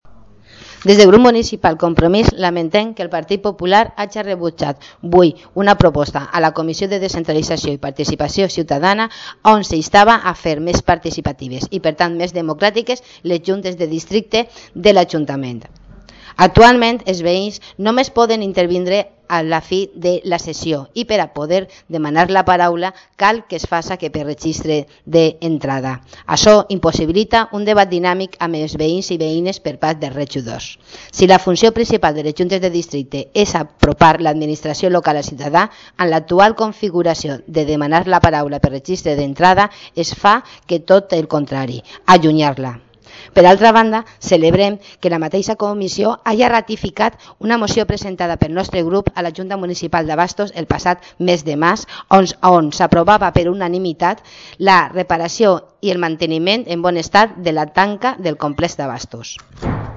Corte de voz Pilar Soriano Participación Juntas (en valencià)